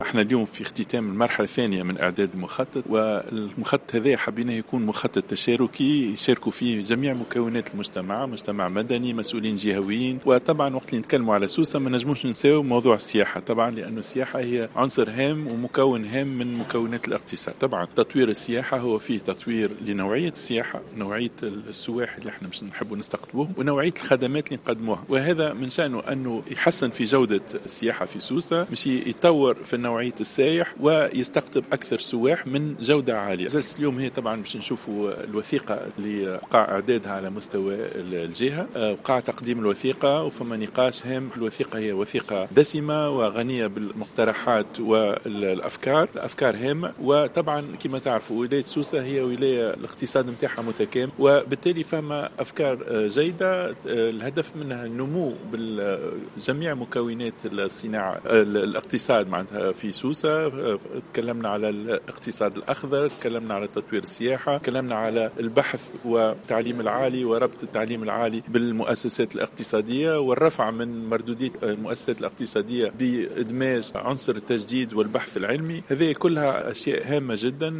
وأفاد في تصريحات لـ "الجوهرة اف أم" ان هذه المقترحات تضمنتها الوثيقة التي تم اعدادها على مستوى الجهة بمناسبة إعداد المخطط الخماسي للتنمية.